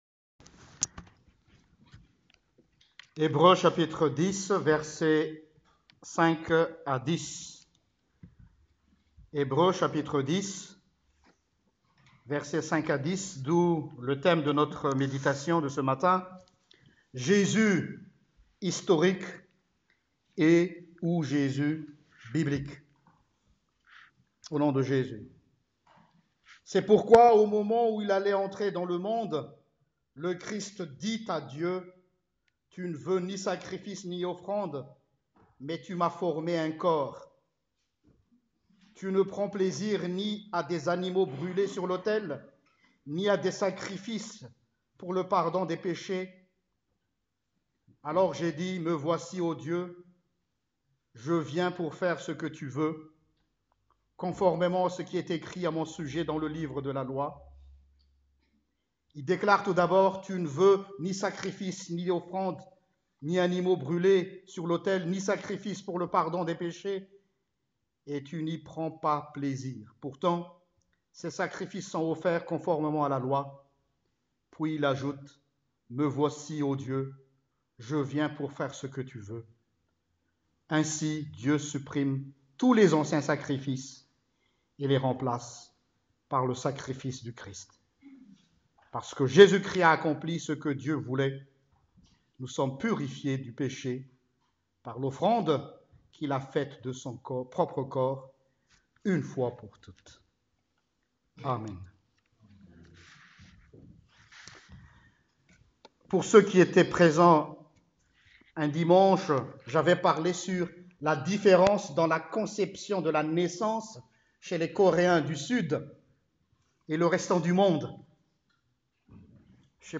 Prédication du 23 Décembre: JÉSUS HISTORIQUE ET (OU) JÉSUS BIBLIQUE